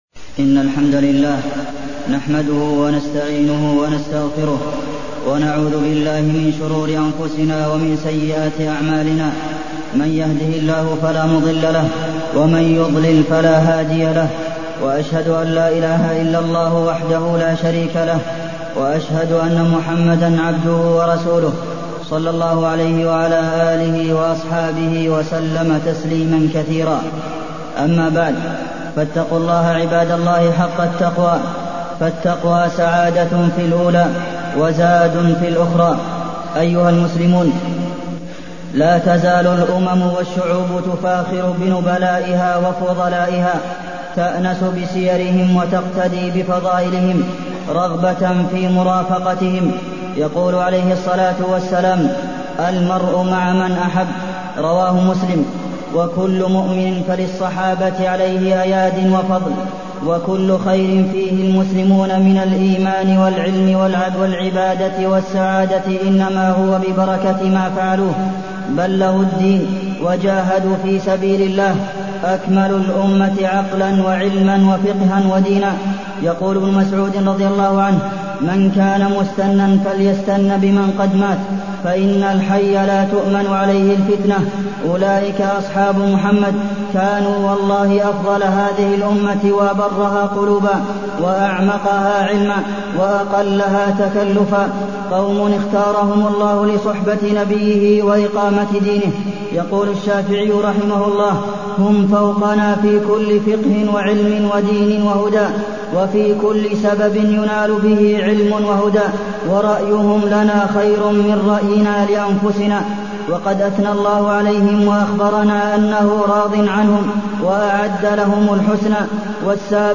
تاريخ النشر ٨ ذو القعدة ١٤٢١ المكان: المسجد النبوي الشيخ: فضيلة الشيخ د. عبدالمحسن بن محمد القاسم فضيلة الشيخ د. عبدالمحسن بن محمد القاسم مناقب أبي بكر